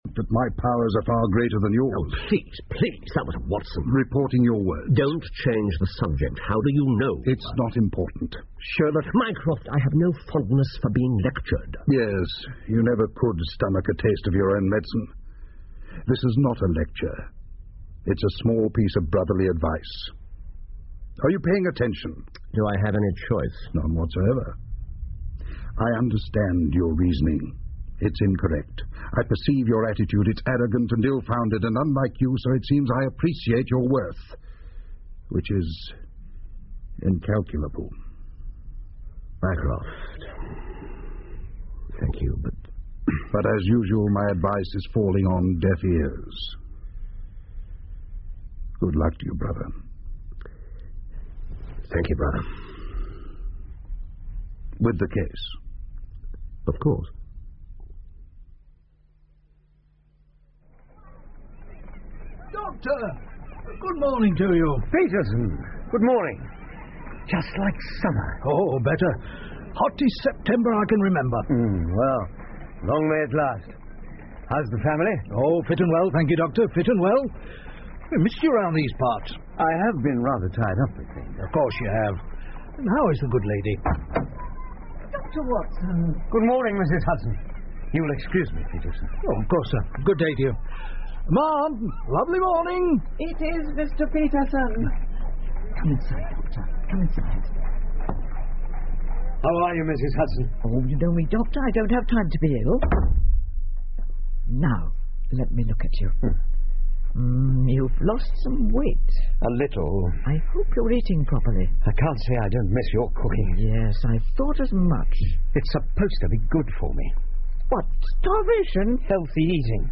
福尔摩斯广播剧 The Retired Colourman 2 听力文件下载—在线英语听力室